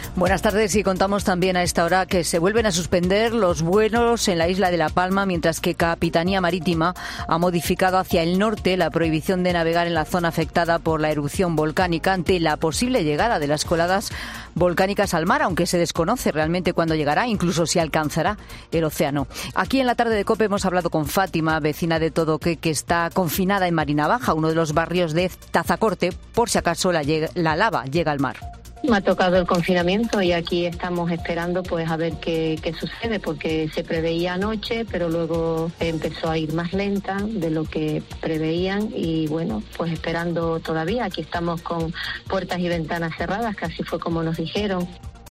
Una vecina de Todoque, en COPE: "Tenemos que seguir confinados, con ventanas y puertas cerradas"